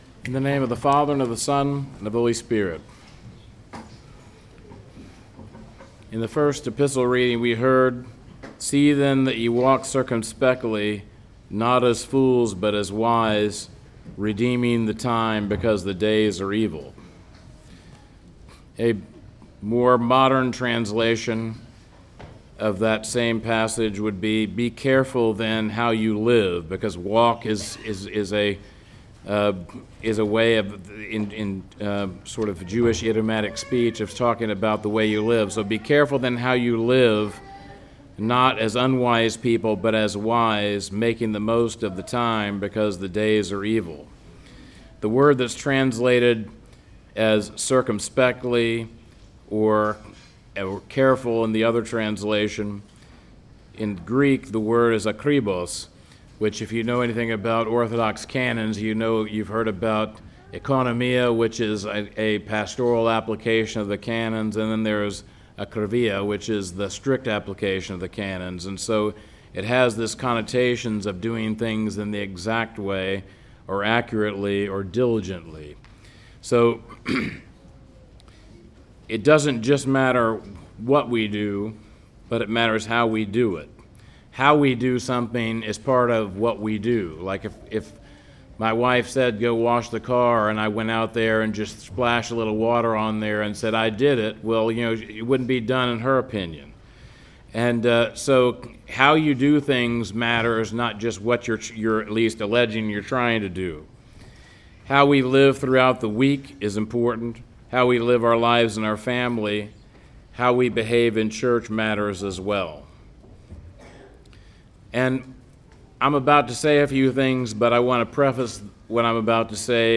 2025 Walking Circumspectly Preacher: